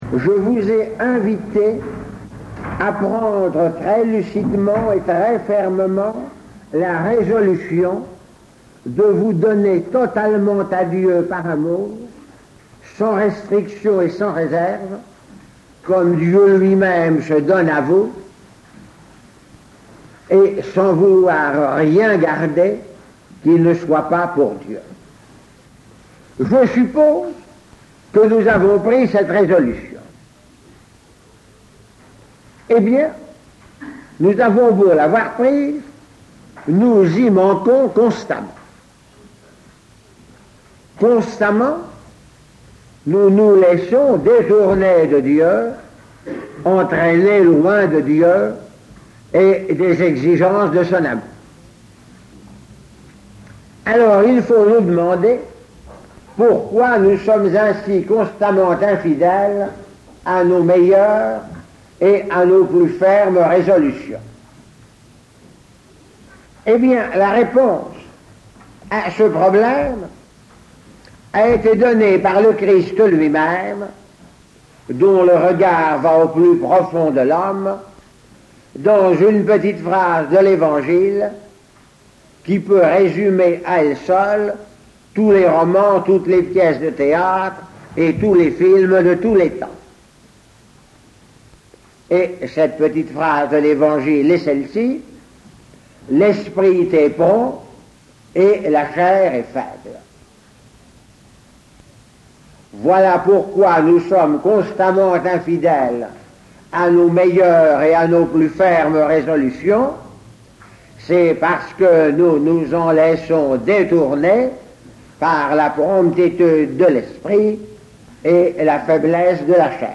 Enseignement
Il s'agit de documents d'archive dont la qualité technique est très médiocre, mais dont le contenu est particulièrement intéressant et tout à fait conforme à l'enseignement de l'Eglise Catholique.